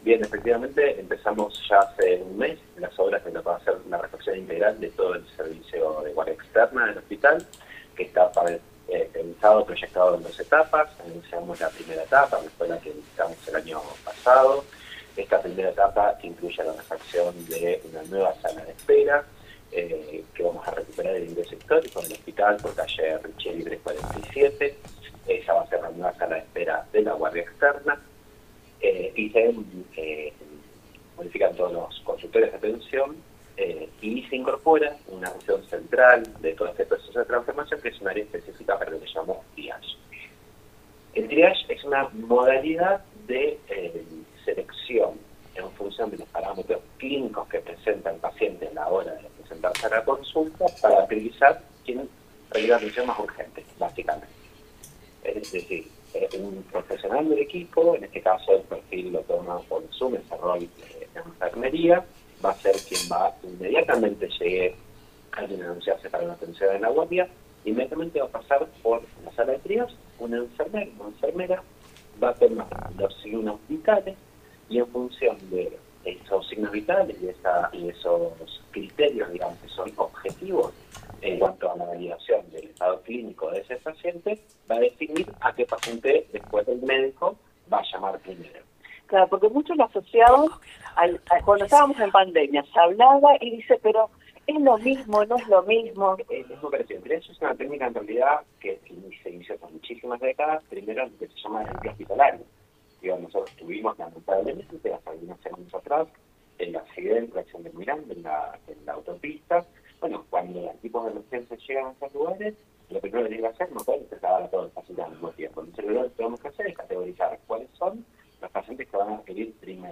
en el móvil del programa “Con Voz” por Radio 102.9